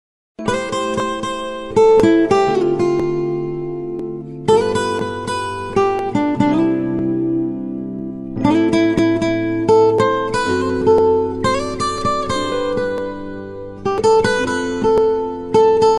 벨소리